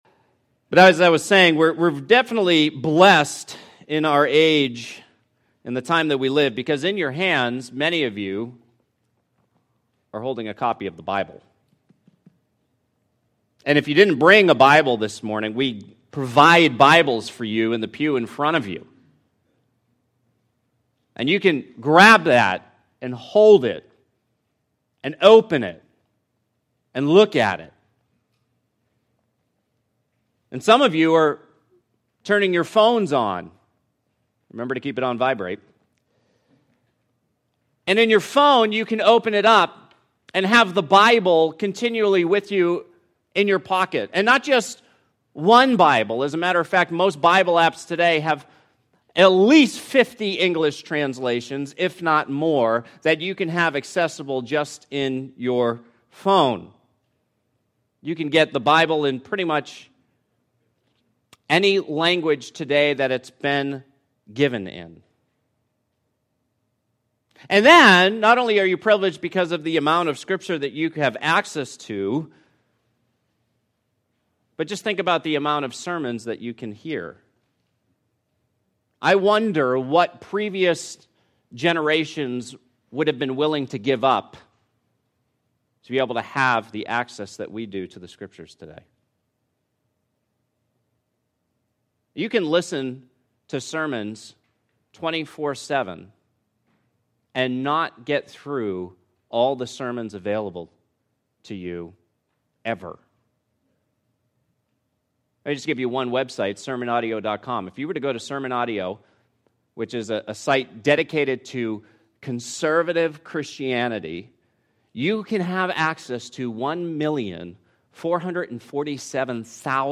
Download Download Reference James 1:19-27 Our Call to the Word James 1:19-27 Today’s sermon entitled “Our Call To The Word” is taken from James chapter one, verses 19-27.